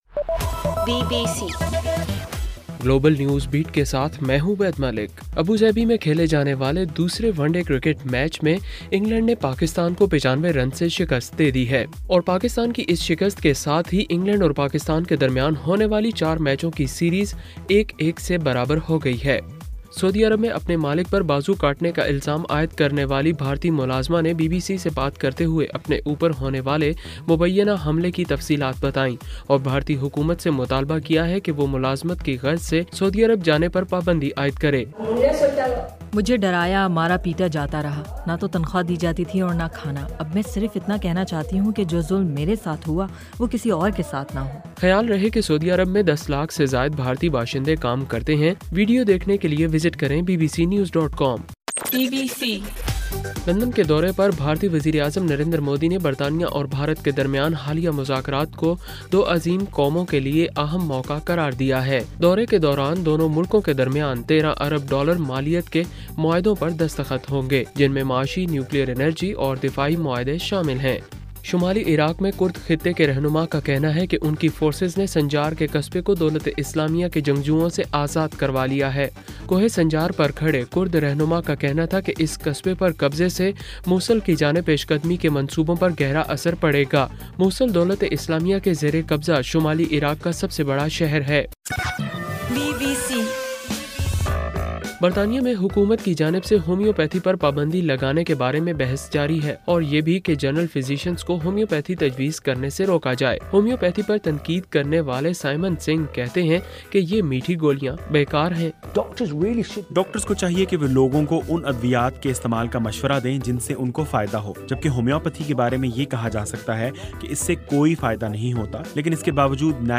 نومبر 13: رات 12 بجے کا گلوبل نیوز بیٹ بُلیٹن